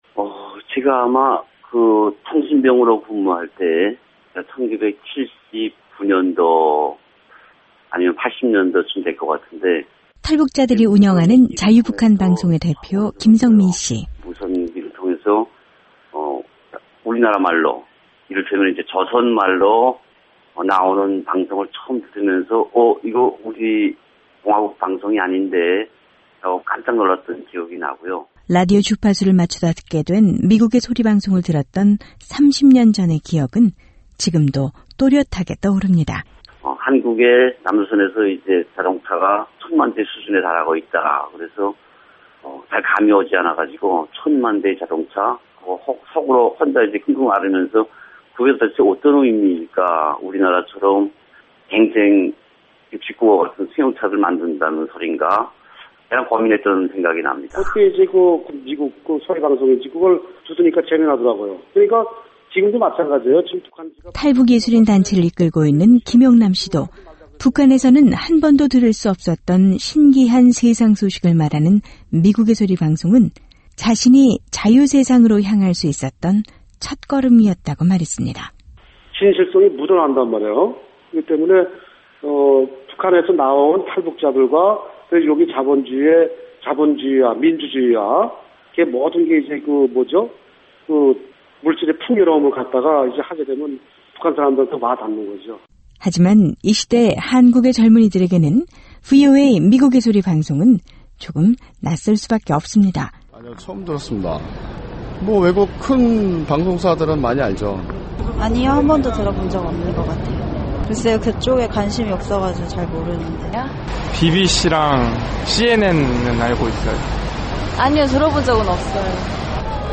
오늘은 한국에 정착한 탈북자들은 VOA를 어떻게 생각하는지, 이 시대 한국 젊은이들은 VOA를 얼마나 알고 있는지, 또 VOA에 대한 어떤 바람을 가지고 있는지 서울시민들을 만나봤습니다.